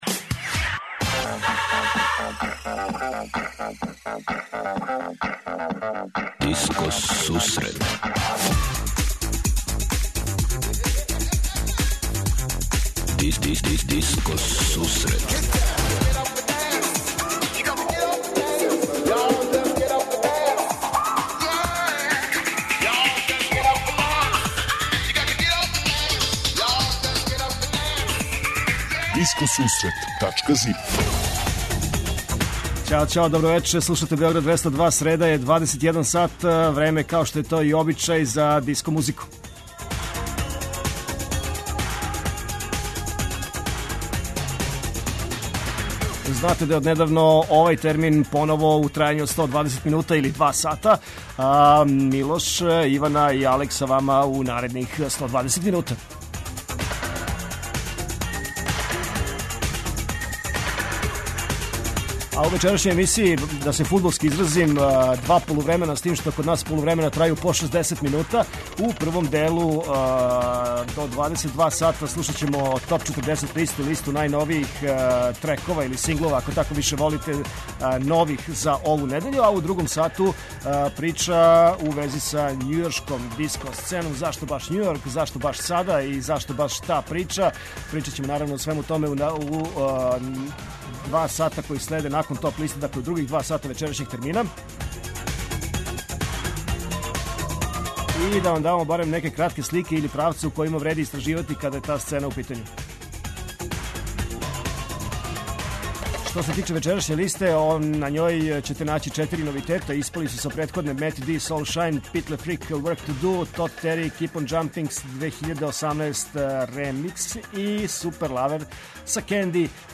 Eмисија посвећена најновијој и оригиналној диско музици у широком смислу.
Заступљени су сви стилски утицаји других музичких праваца - фанк, соул, РнБ, итало-диско, денс, поп. Сваке среде се представља најновија, актуелна, Топ 40 листа уз непосредан контакт са слушаоцима и пуно позитивне енергије.